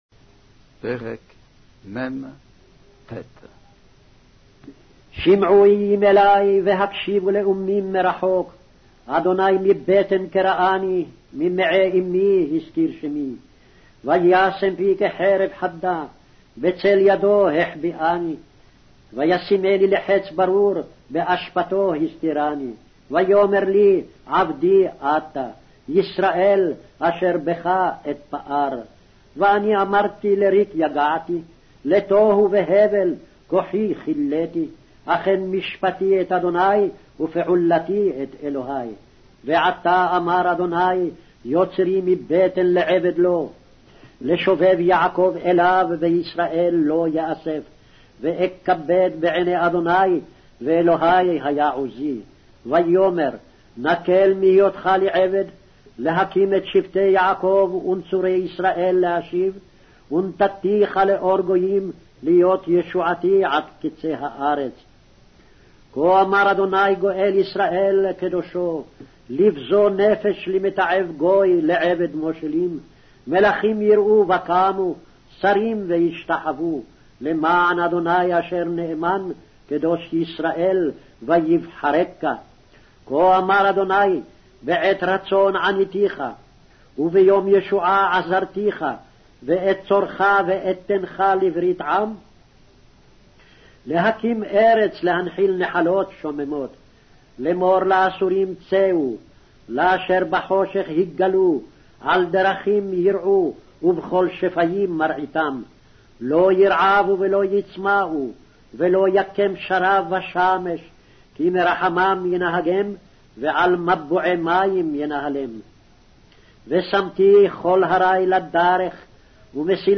Hebrew Audio Bible - Isaiah 52 in Guv bible version